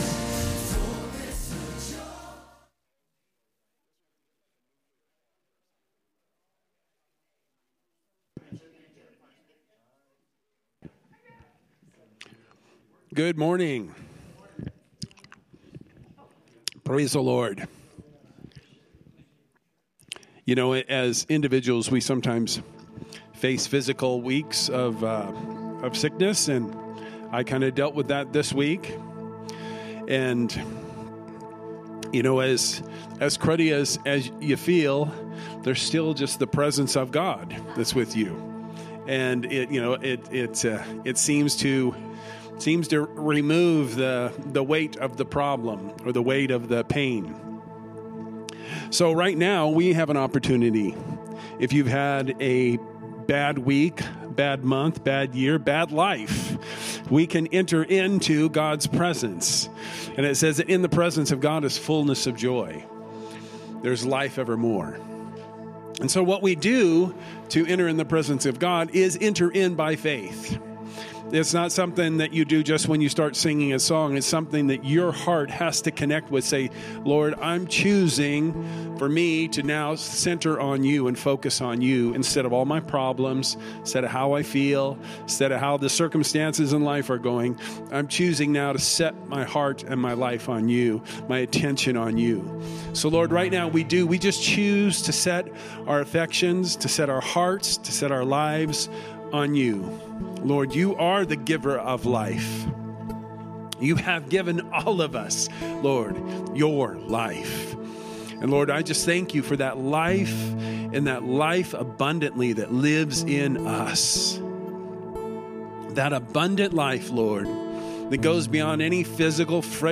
From Series: "Sermon"